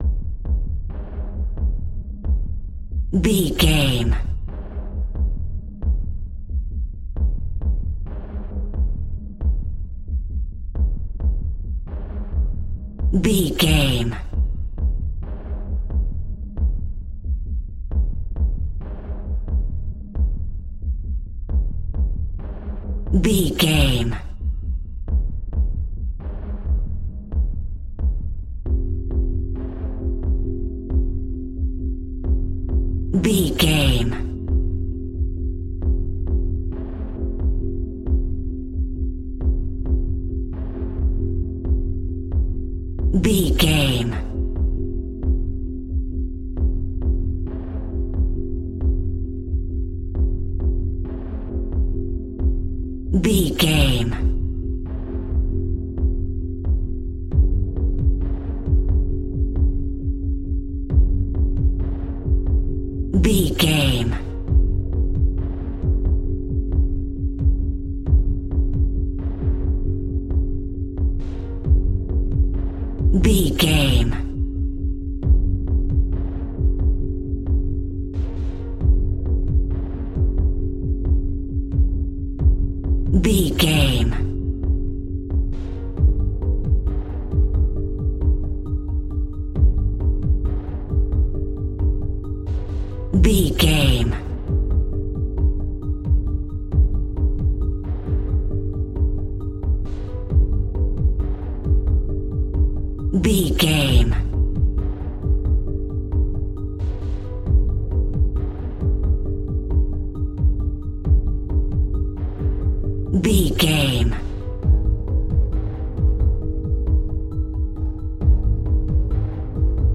In-crescendo
Thriller
Atonal
scary
ominous
dark
haunting
eerie
synthesiser
drum machine
instrumentals
mysterious
tense